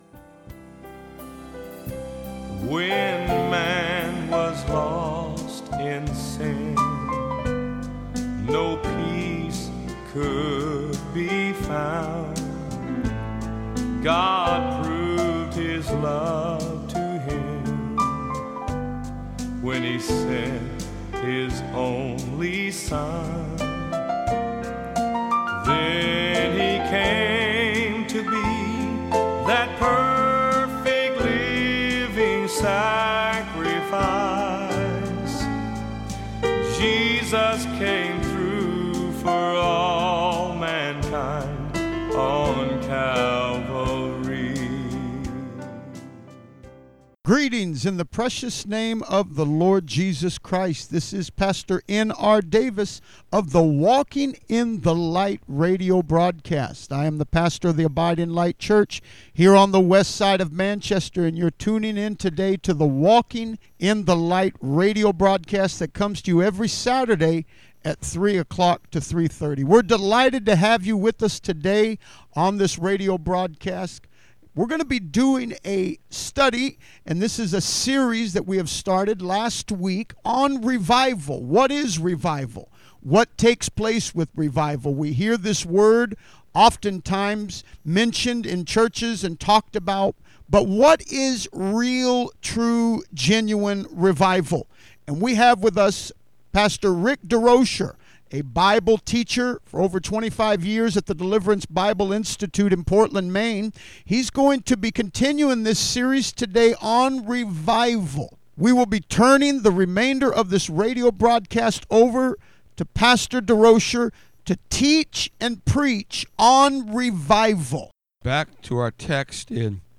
2014 Revival Study 3 - radio broadcast.mp3